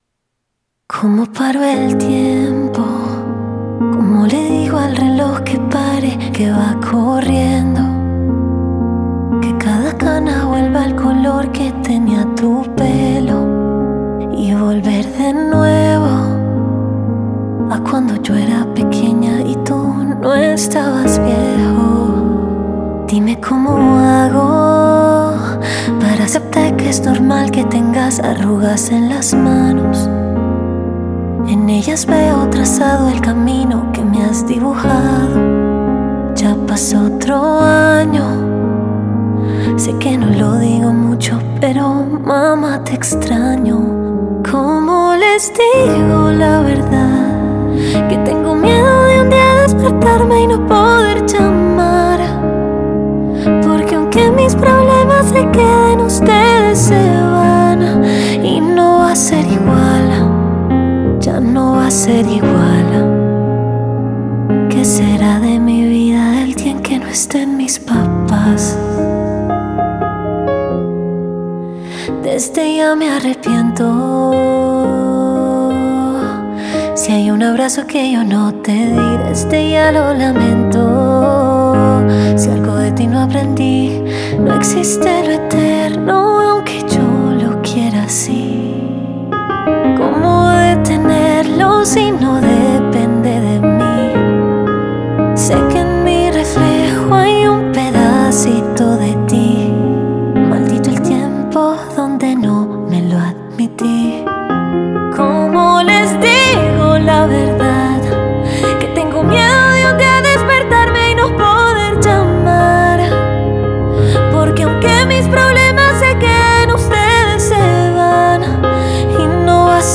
este 29 de enero la cantante y compositora colombiana
Con una letra cargada de nostalgia, amor y vulnerabilidad